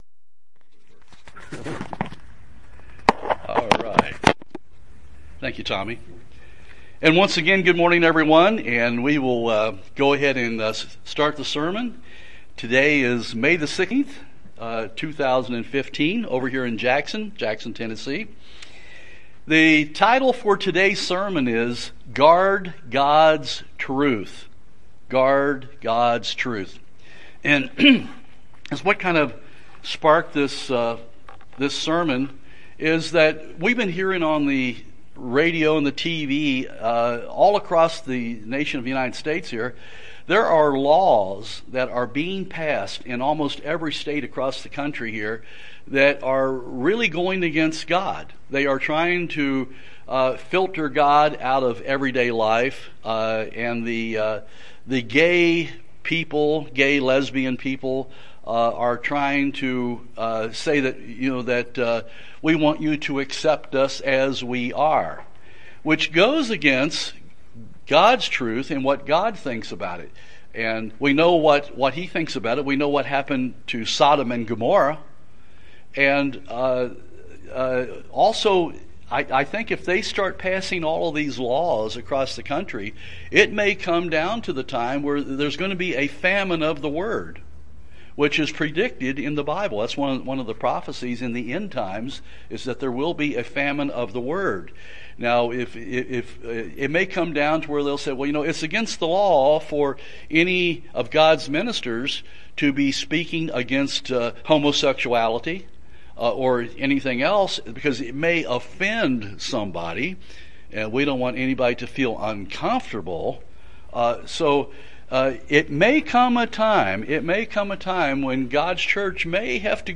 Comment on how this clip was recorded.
Given in Jackson, TN